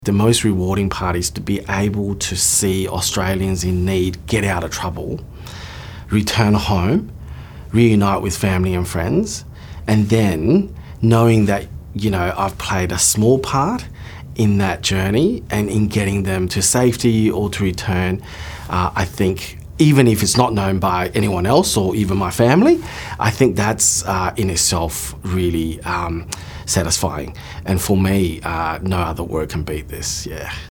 a consular officer